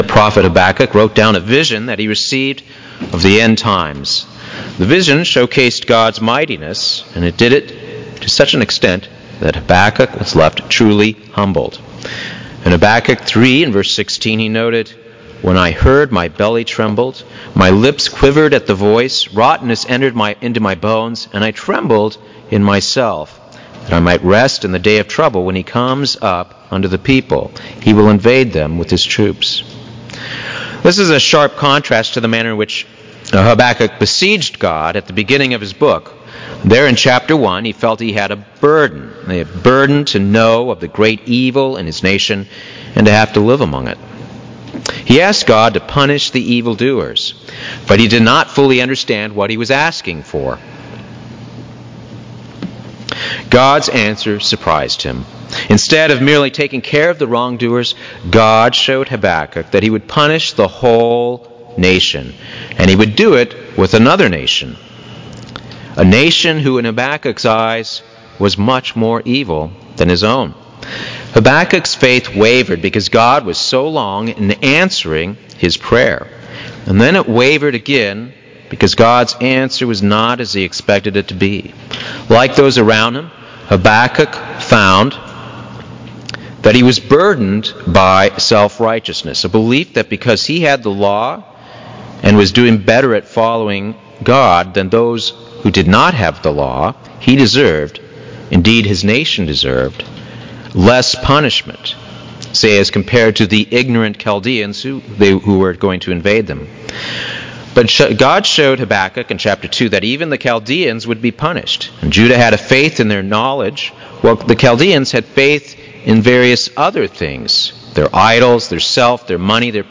Given in Buford, GA
UCG Sermon Studying the bible?